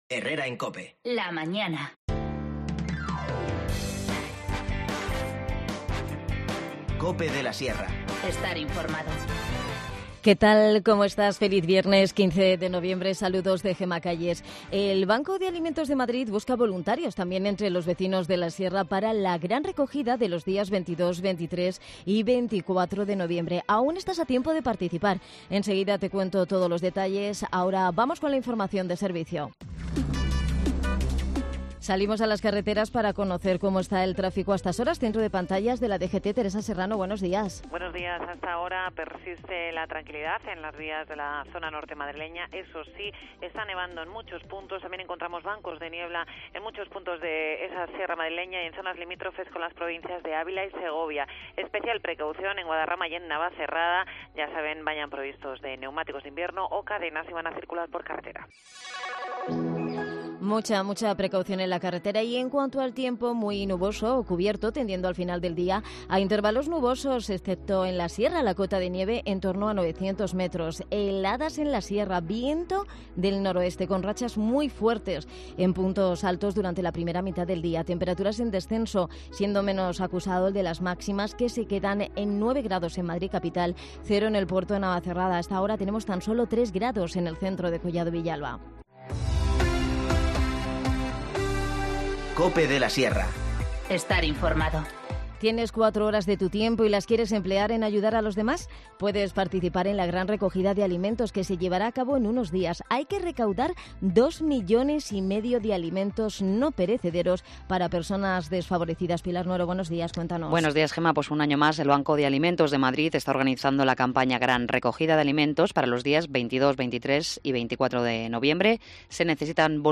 AUDIO: El Boalo-Cerceda-Mataelpino celebra las VI Jornadas Gastronómicas del Cerdo Ibérico. Hablamos con algunos de los establecimientos participantes